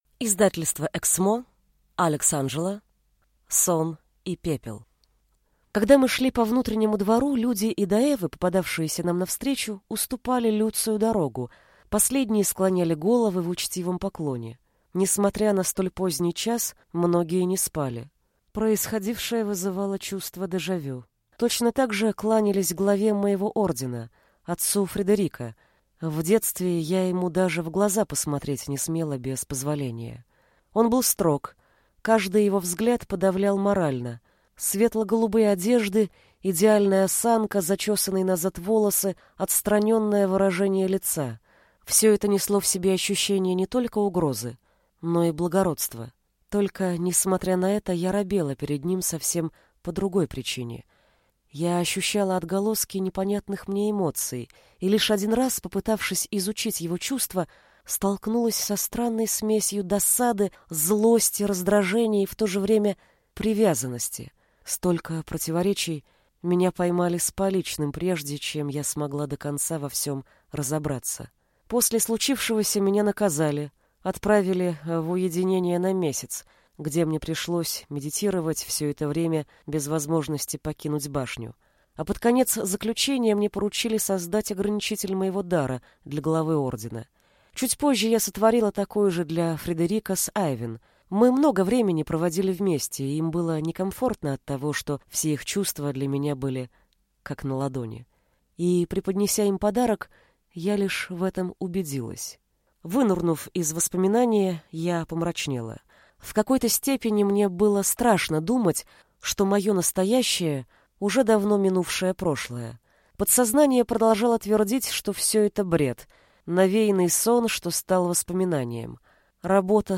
Аудиокнига Сон и Пепел | Библиотека аудиокниг
Прослушать и бесплатно скачать фрагмент аудиокниги